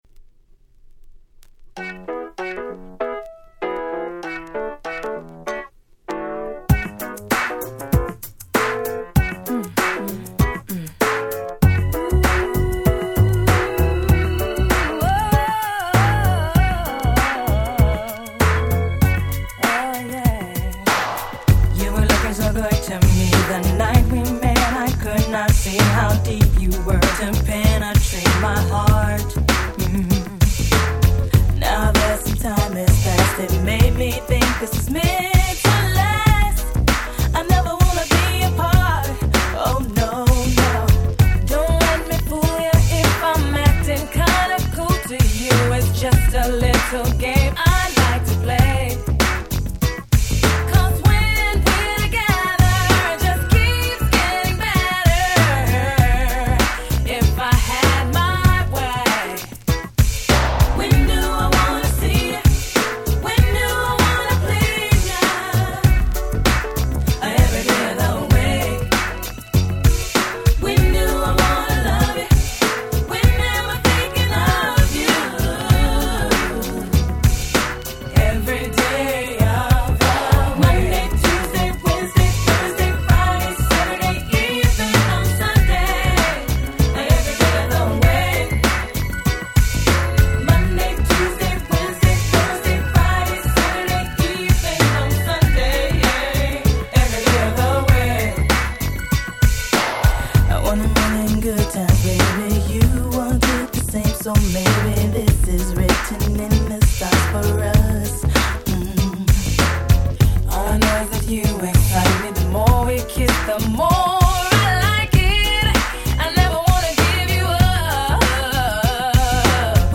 94' Big Hit R&B !!
問答無用の90's R&B Classics !!